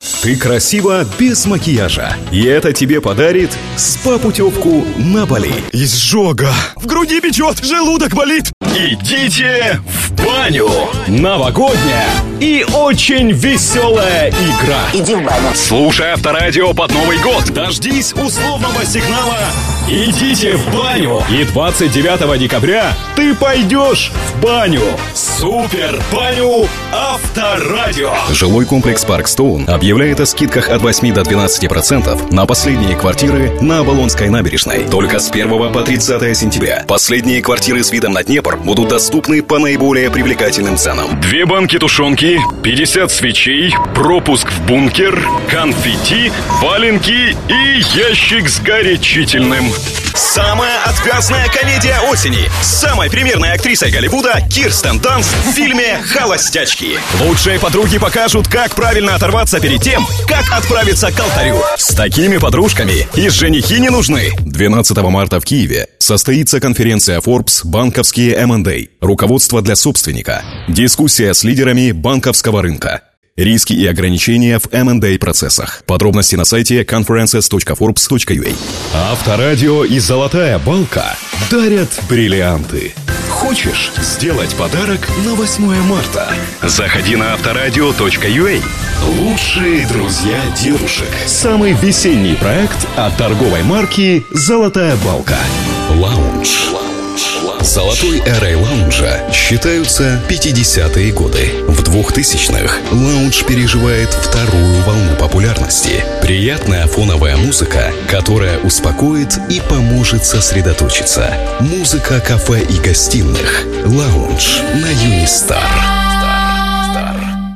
Тракт: Rode NT1A, TC Electronic Konnekt 8